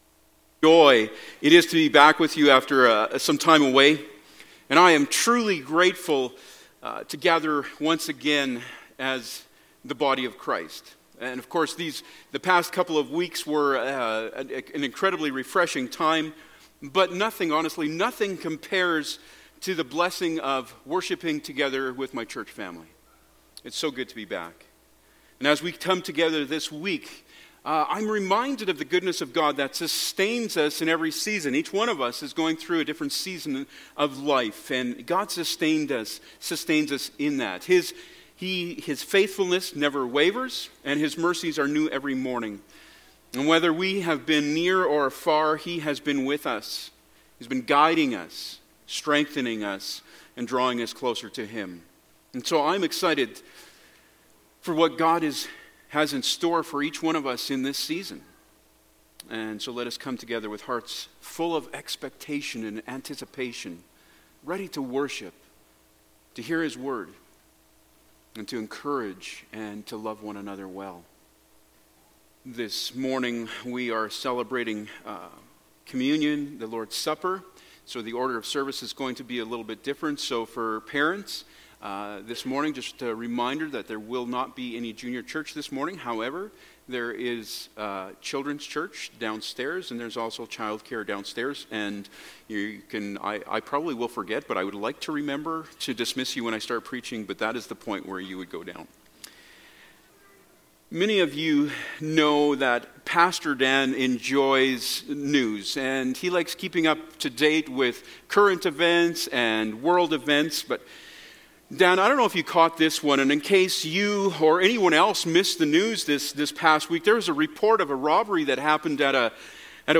2 Service Type: Sunday Morning Topics: Repentance